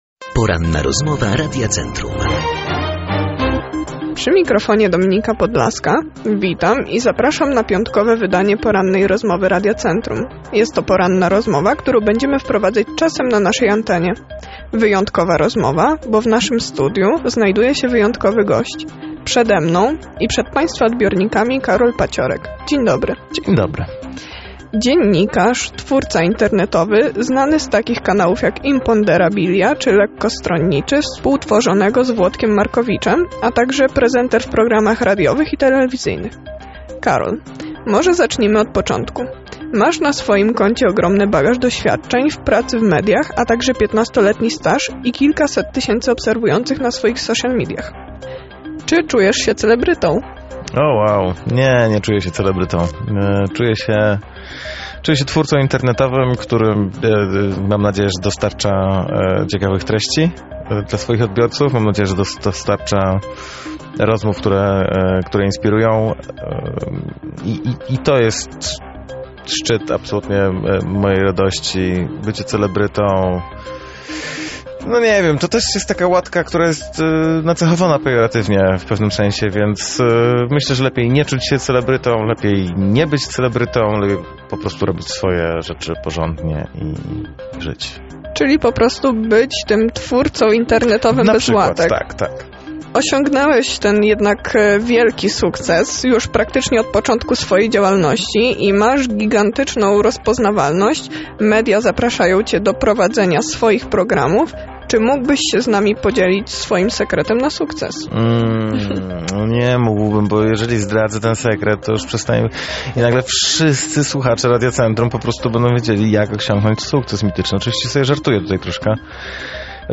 W dzisiejszej porannej rozmowie zdradził, że to właśnie teraz jest najlepszy moment aby tworzyć w social mediach.
ROZMOWA-14-1.mp3